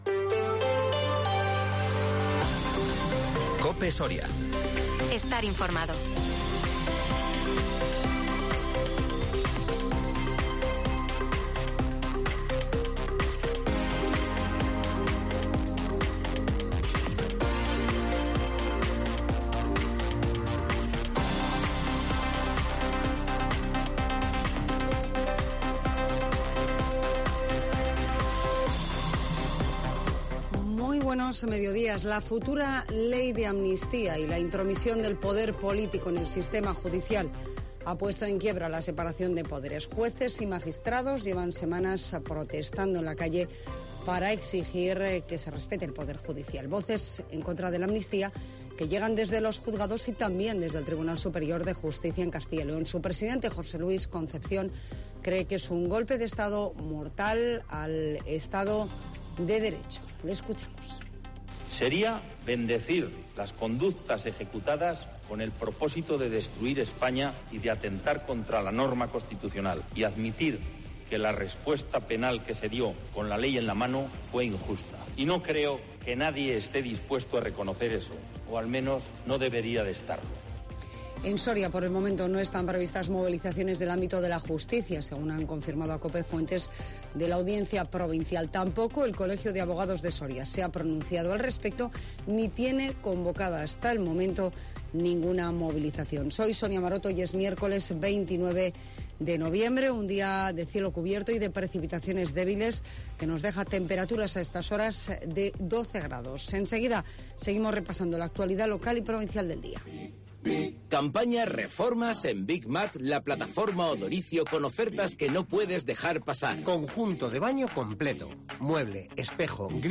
Las noticias en COPE Soria